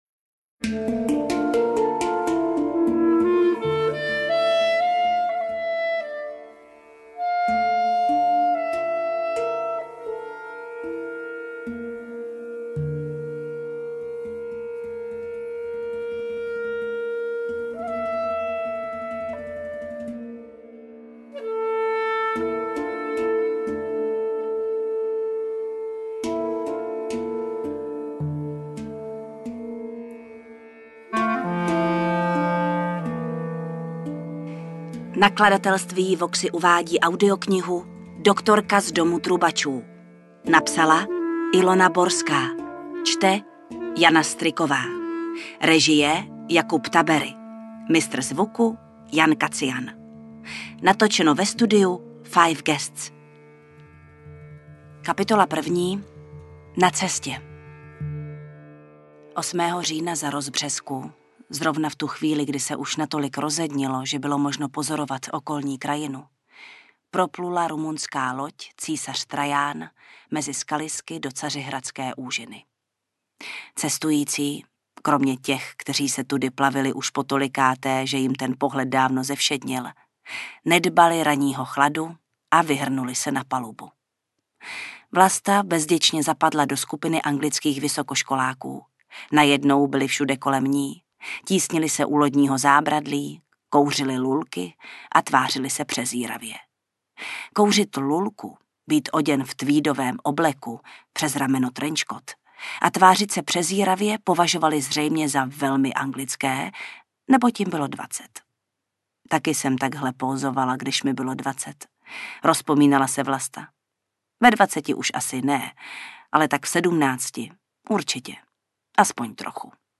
Interpret:  Jana Stryková
AudioKniha ke stažení, 31 x mp3, délka 12 hod. 40 min., velikost 522,4 MB, česky